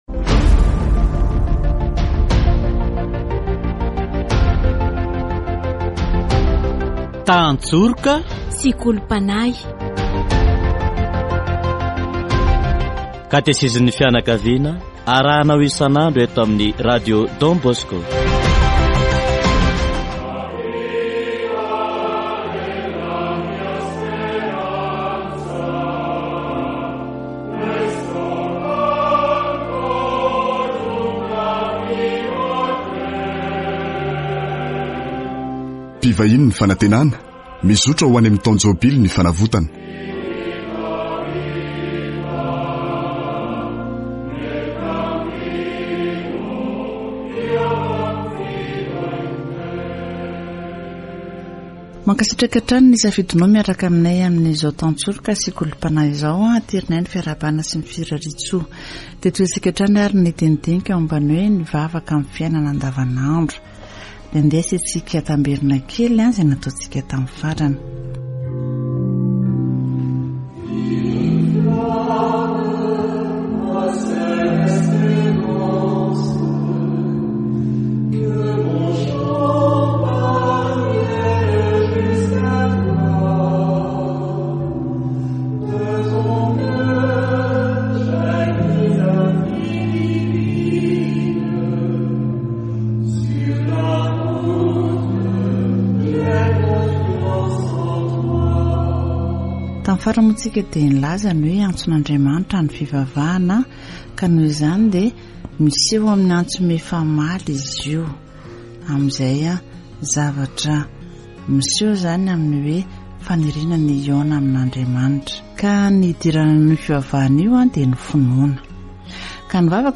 Catechesis on “Daily prayer”